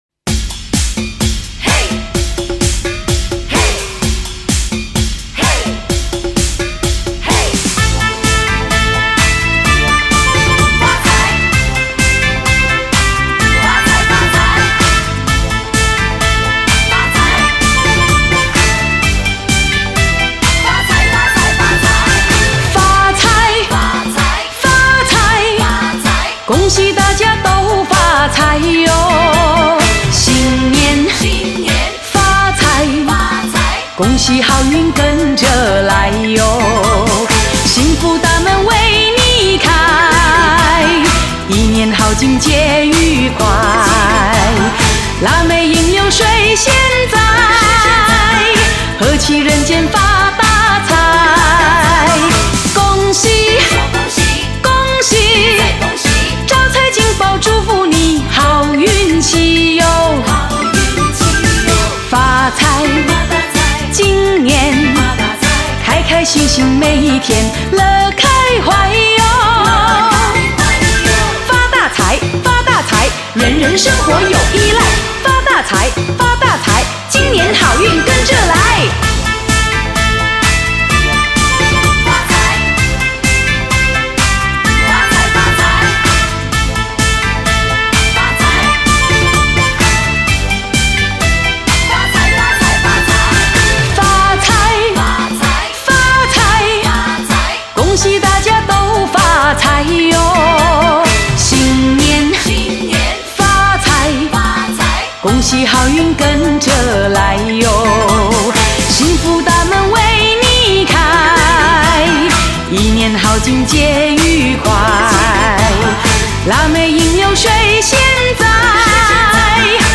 来源：DVD压制